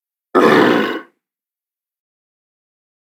beast_roar_grunt.ogg